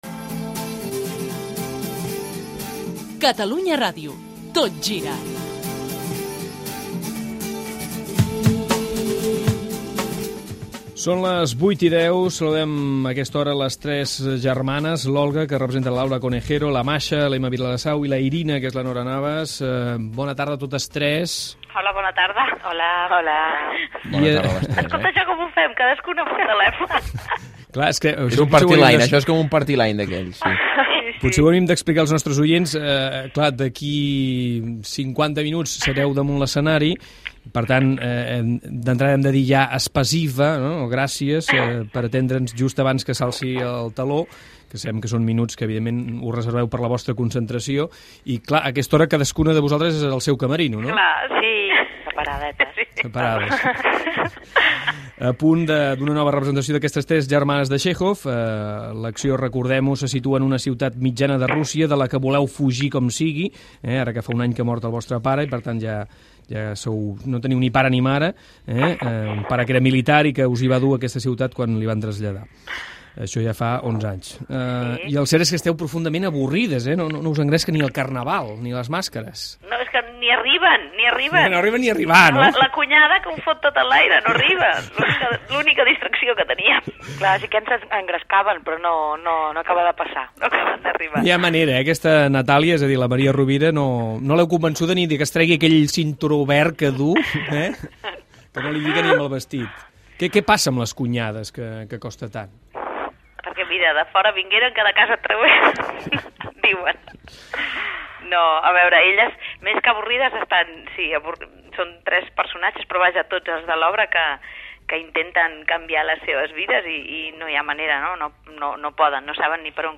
Indicatiu del programa, entrevista telefònica a les actrius Nora Navas, Laura Conejero i Emma Vilarasau, que interpreten "Les tres germanes", de Txèkhov, amb informació de dos gols al camp de l'Atlético de Madrid en el partit contra l'Albacete
Entreteniment Esportiu